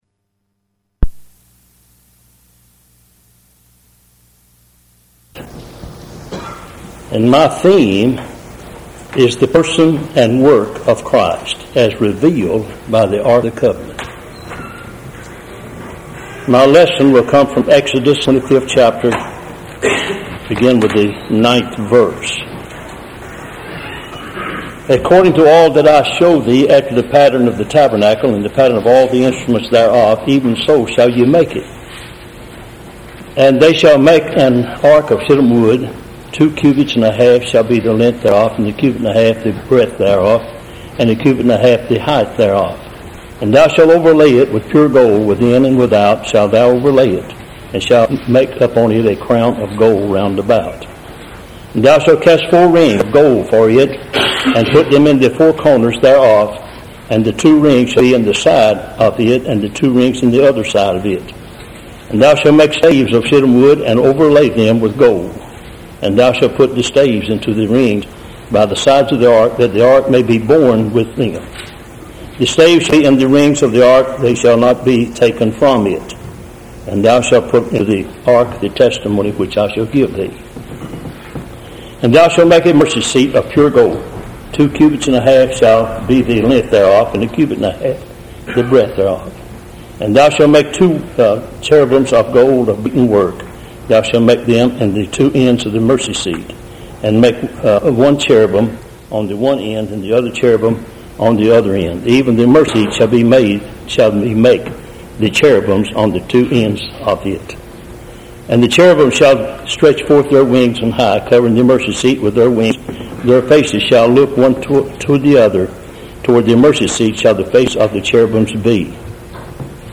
Lesson 9 from the 2000 session of the Old Union Ministers School.